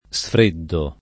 sfreddo [ S fr % ddo ]